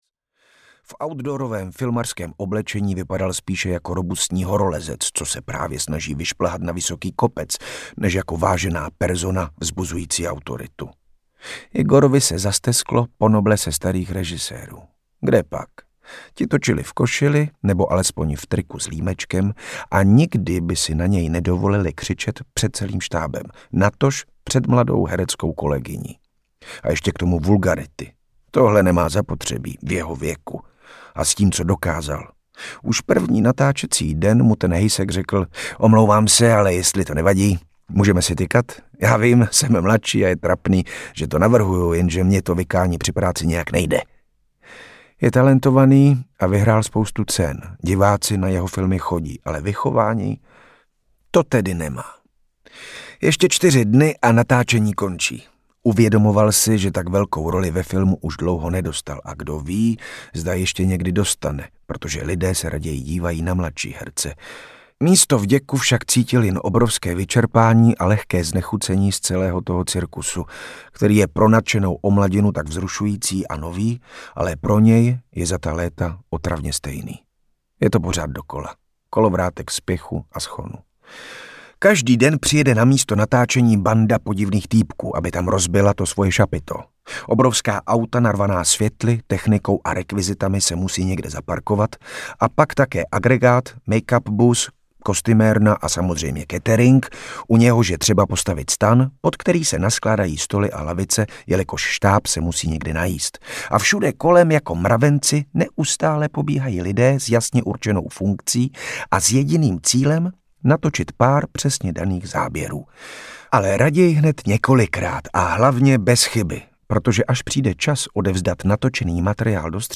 Hvězdy na cestě audiokniha
Ukázka z knihy
• InterpretRichard Krajčo